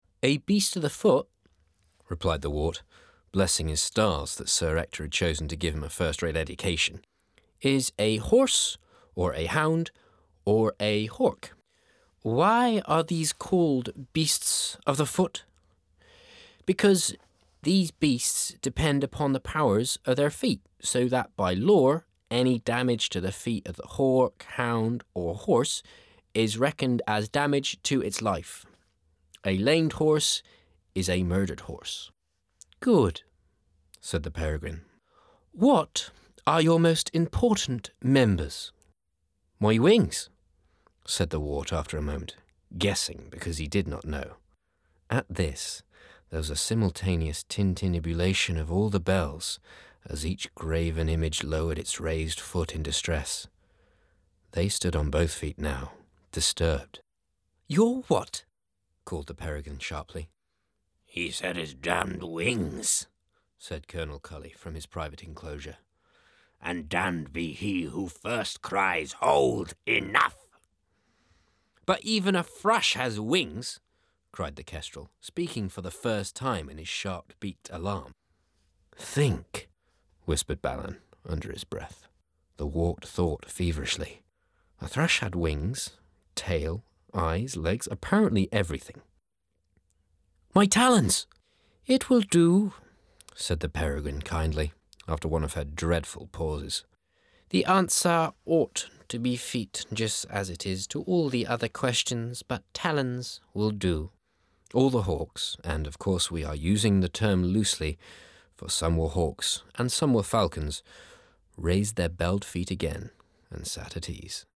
::download:: A Book Extract